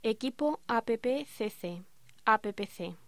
Locución: Equipo APPCC, APPC